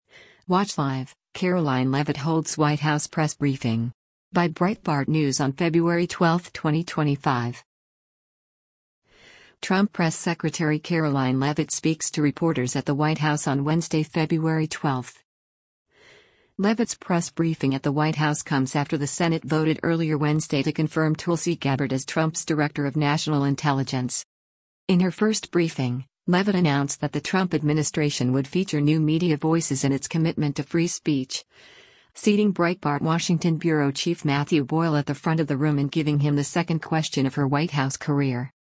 Trump Press Secretary Karoline Leavitt speaks to reporters at the White House on Wednesday, February 12.